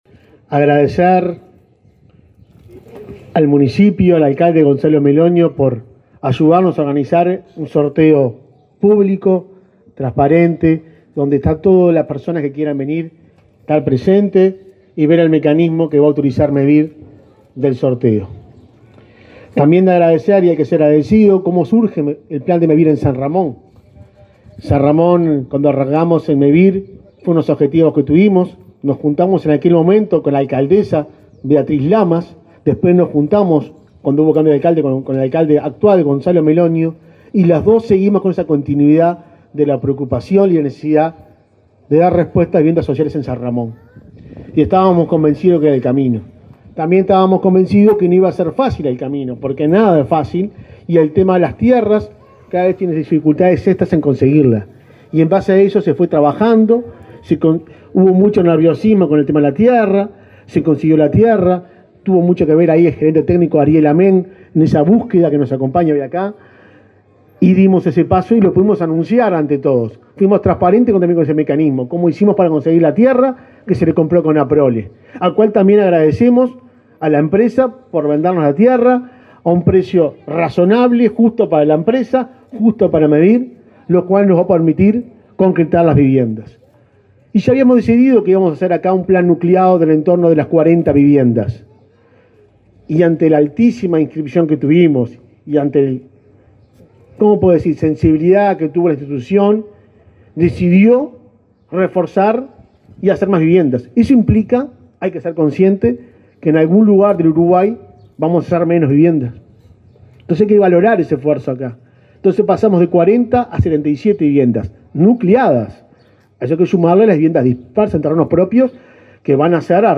Palabras de autoridades en sorteo de Mevir en Canelones
Mevir realizó, este viernes 18 en la localidad de San Ramón, departamento de Canelones, el sorteo entre aspirantes para el plan de viviendas nucleadas
El presidente del organismo, Juan Pablo Delgado, y el subsecretario de Vivienda, Tabaré Hackenbruch, señalaron en su oratoria la importancia de este proyecto para la zona.